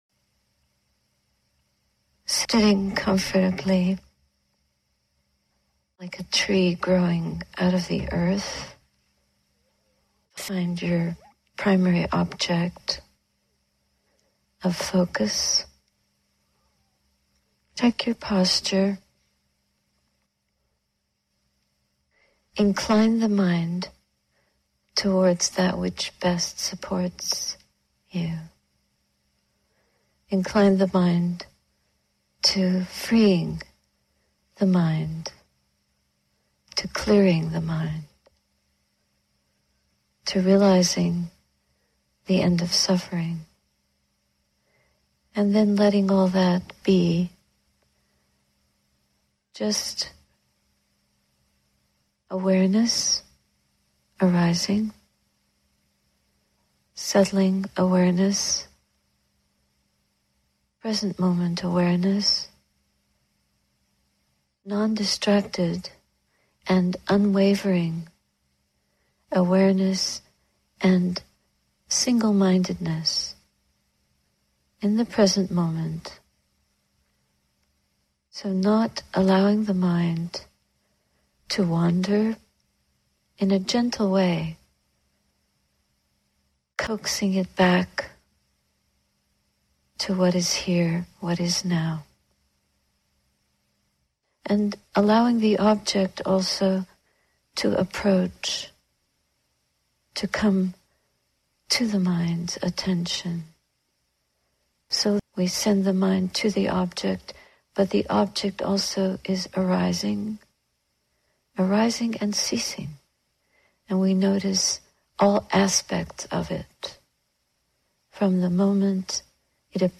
Our True Inheritance – Guided Meditation – Sati Saraniya Hermitage
We wake up to our true inheritance – the liberating Truth of what we are. Guided meditation reflections at Insight Meditation Society, Massachusetts, USA, 2019